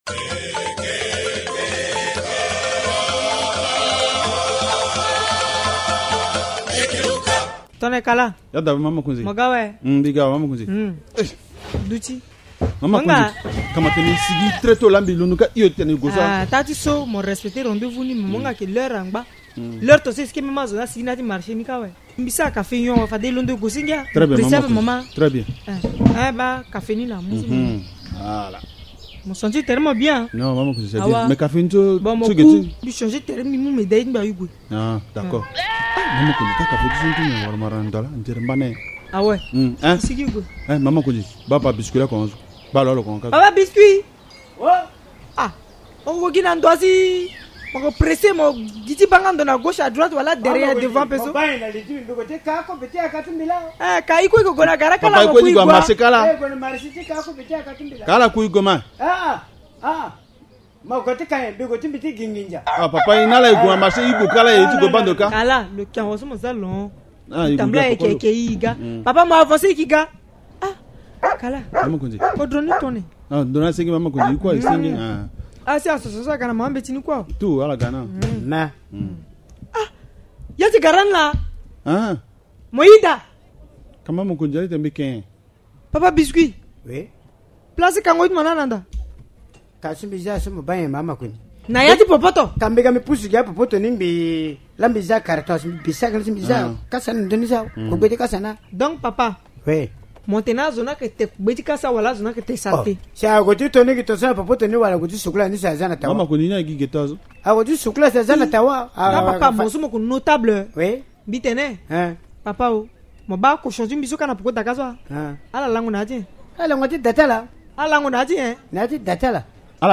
Les comédiens du village Linga attirent l’attention sur l’insalubrité dans les marchés